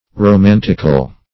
Romantical \Ro*man"tic*al\, a.